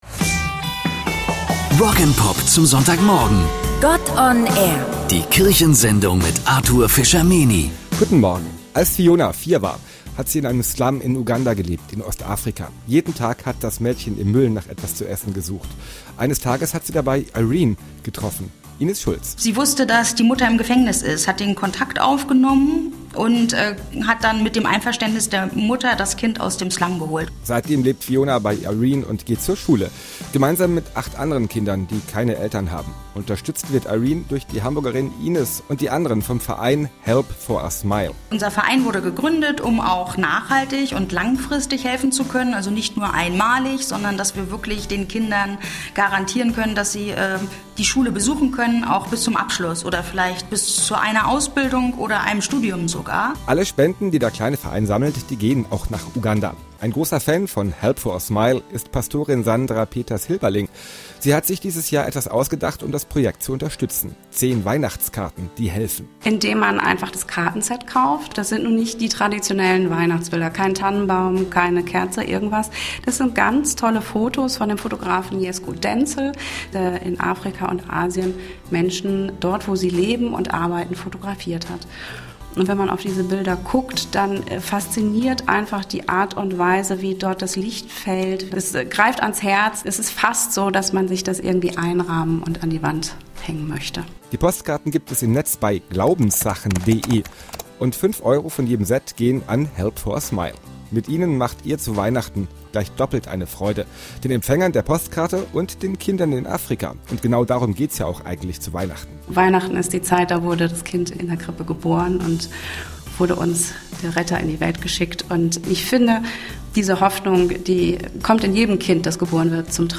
Dank der lieben Kollegen des epn gab es einen Radio-Beitrag zu unserer Arbeit und der aktuellen Kartenaktion. Ausgestrahlt wurde dieser auf Alsterradio und Radio Hamburg im Rahmen der kirchlichen Sendungen.